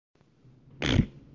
放屁 - 声音 - 淘声网 - 免费音效素材资源|视频游戏配乐下载
屁声。